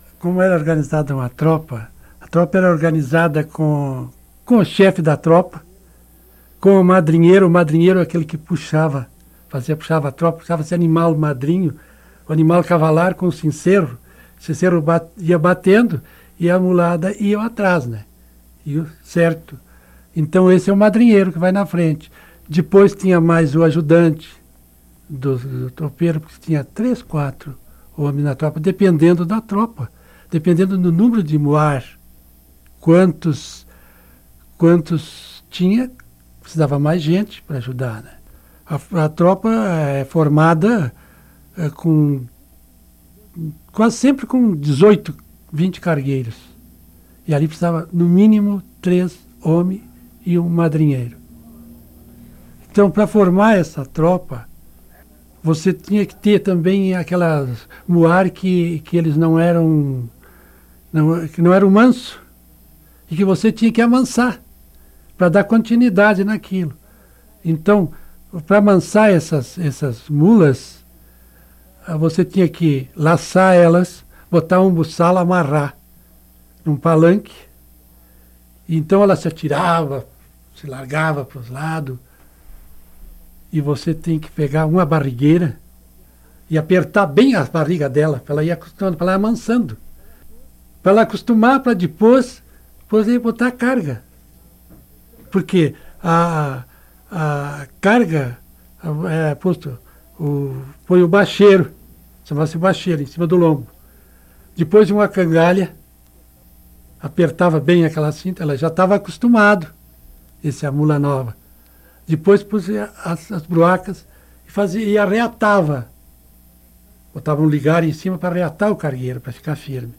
Unidade Banco de Memória Oral